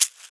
Shaker (Beads-B).wav